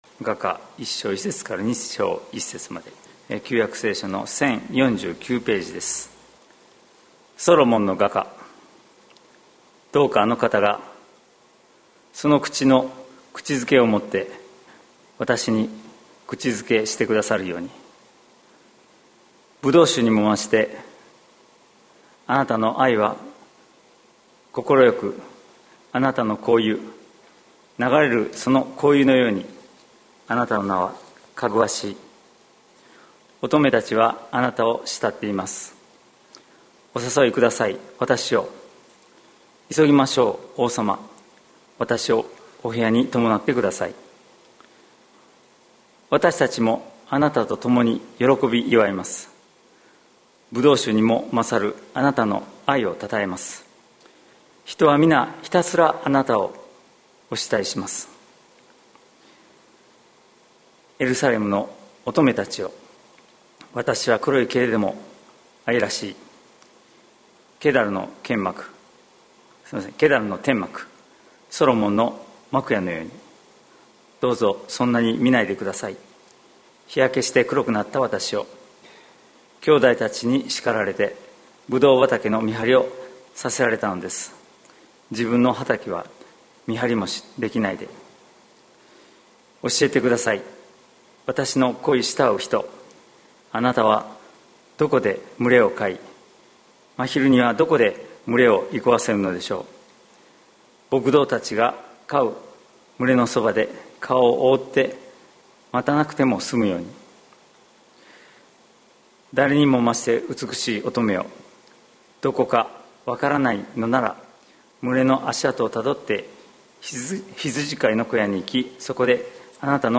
2020年11月29日朝の礼拝「綺麗さと美しさ」関キリスト教会
説教アーカイブ。